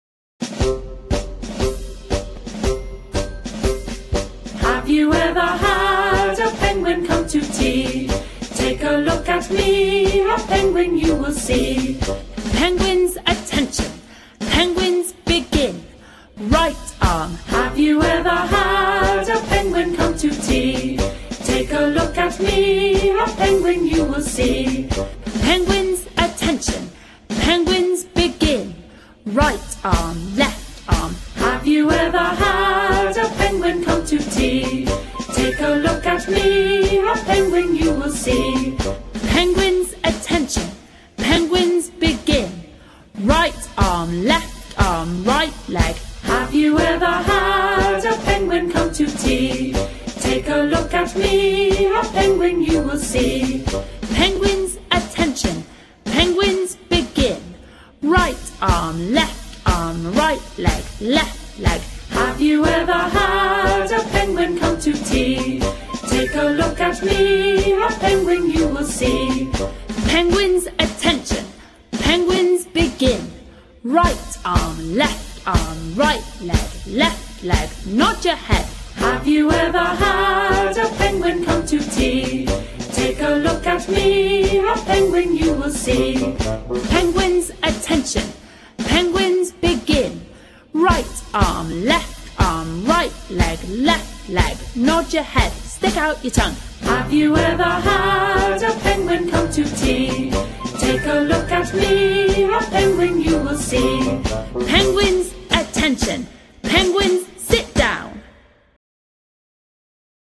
If you want to warm up at home before you practice any of the songs, there is a great recording here so you can sing along with it at home.